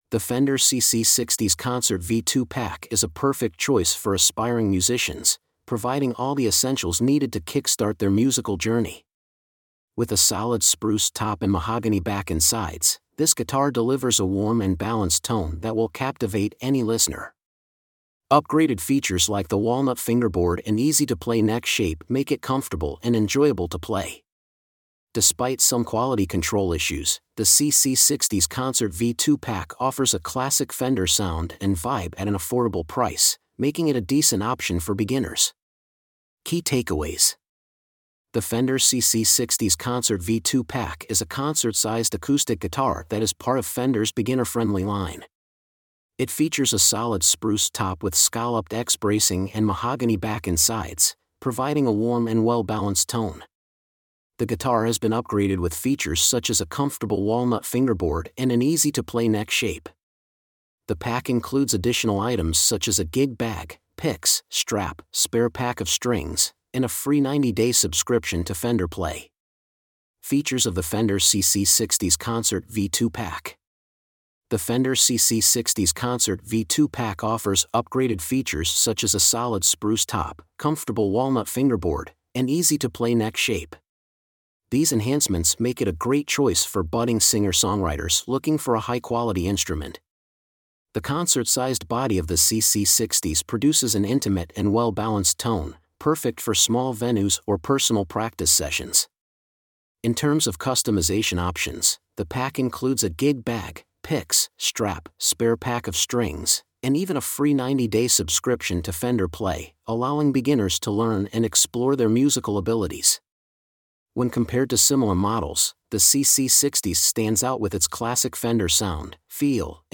Fender CC-60s Concert V2 Review.mp3